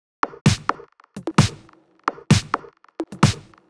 Drum Loops 130bpm